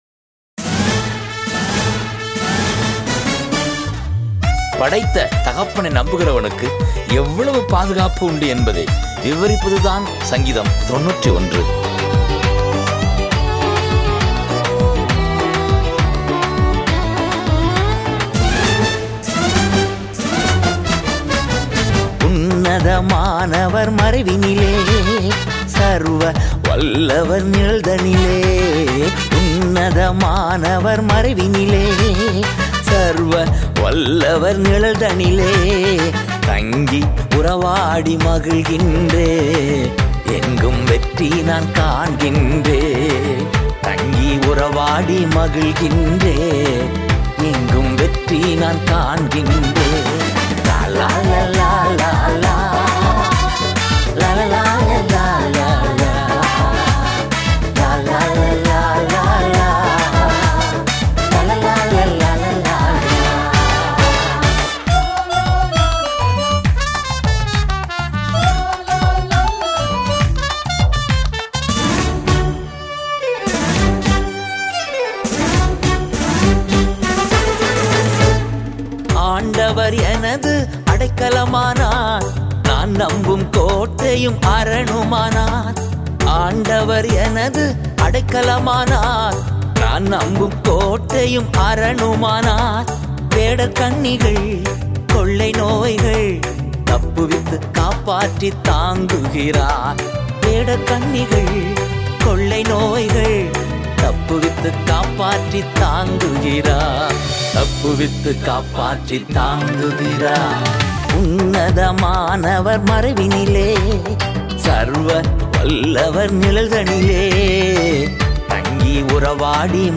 is a christian devotional album songs